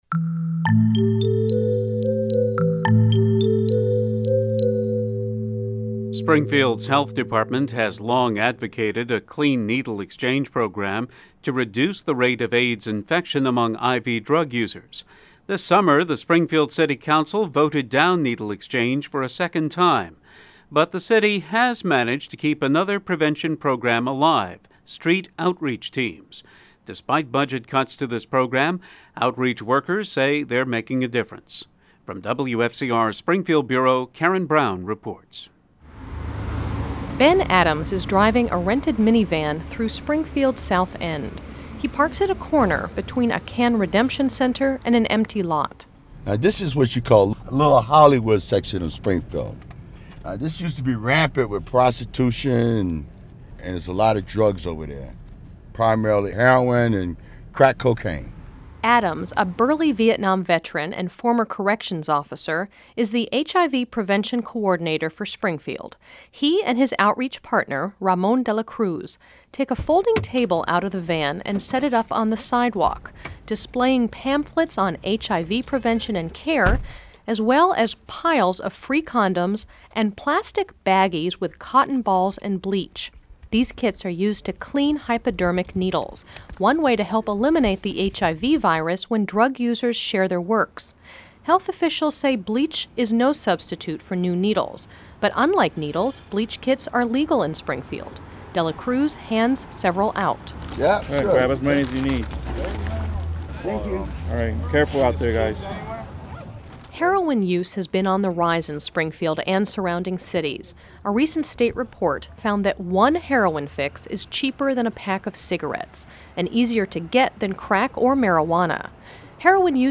Aired on WFCR in 2006
Although Springfield, Mass has failed to enact a needle exchange program against HIV infection, the city has maintained one prevention program — street outreach teams that distribute condoms, transport to detox, and distribute educational materials. We spend a day with two outreach workers trying to “reduce the harm” of I-V drug use.